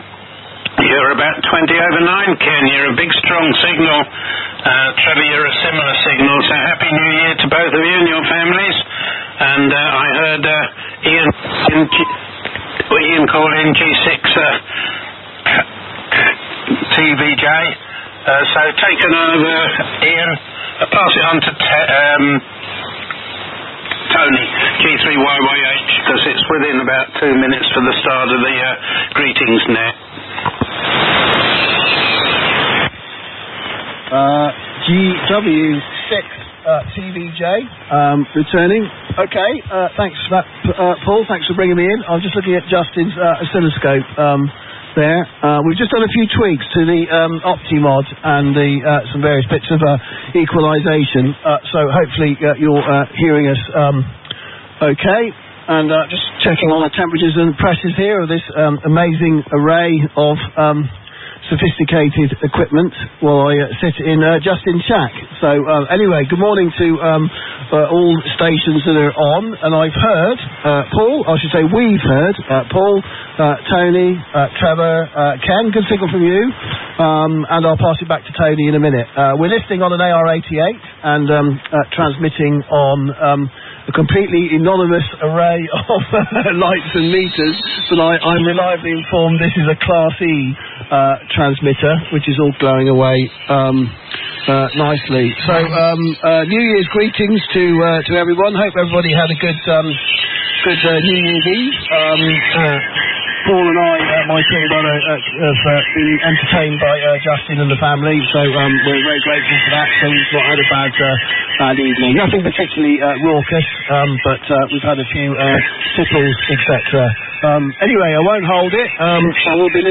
In this section you will find details of radio nets run by the Vintage & Military Amateur Radio Society and by members under their personal arrangements.